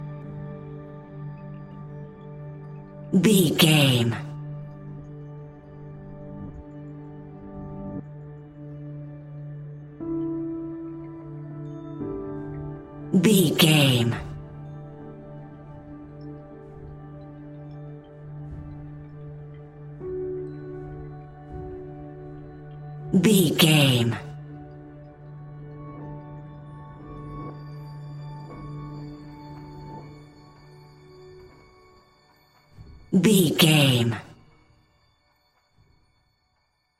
Ionian/Major
D♭
laid back
Lounge
sparse
new age
chilled electronica
ambient
atmospheric